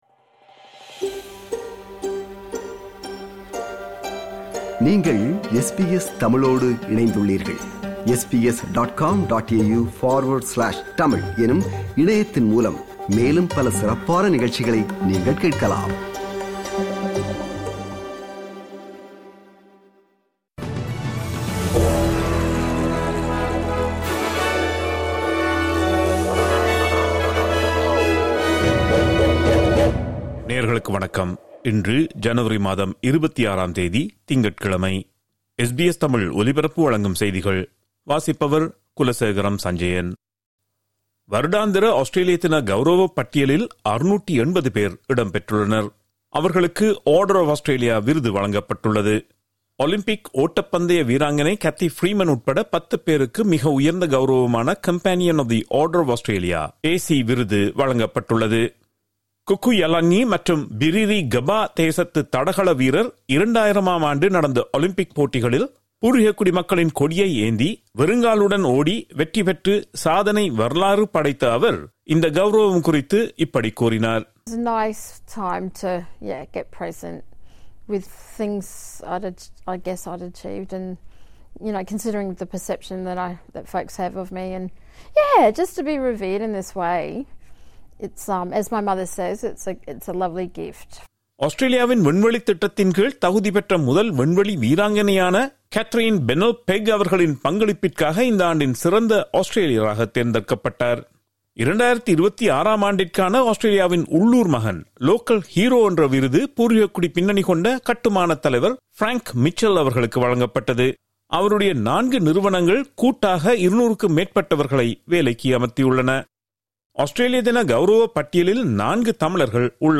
இன்றைய செய்திகள்: 26 ஜனவரி 2026 - திங்கட்கிழமை
SBS தமிழ் ஒலிபரப்பின் இன்றைய (திங்கட்கிழமை 26/01/2026) செய்திகள்.